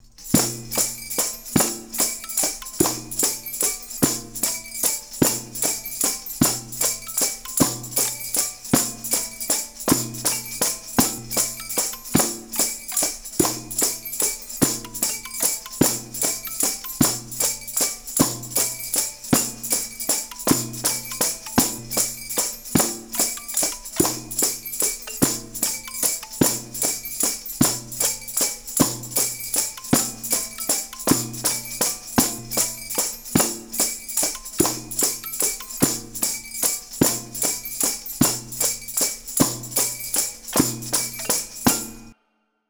Percusiones tradicionales de España (bucle)
pandereta
percusión
botella
melodía
repetitivo
rítmico